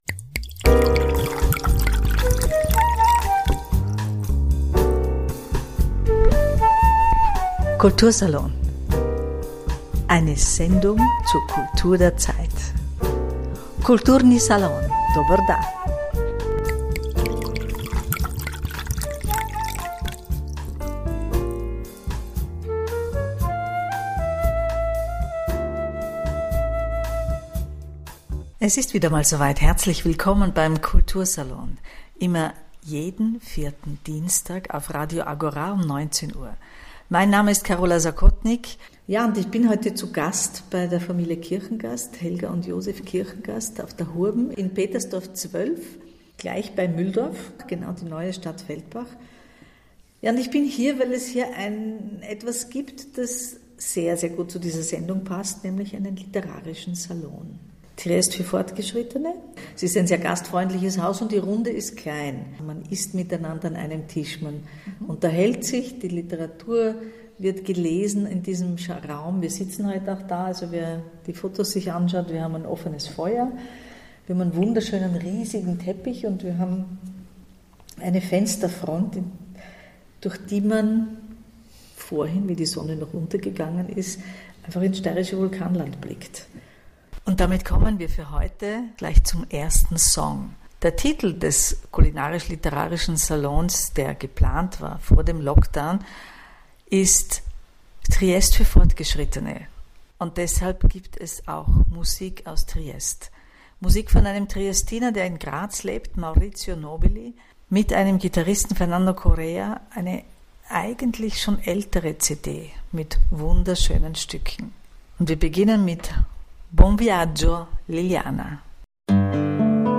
Wie das mit dem Potenzial zusammenhängt, das in unserem aktuellen gesellschaftlich politischen Geschehen gerade nicht gehoben wird, erörtern die drei in einem sinnlich, genüsslichen Gespräch, das einlädt, sich selbst auch gleich etwas Triestinisches zu kochen. Radio für die Seele, den Geist und auch den Bauch.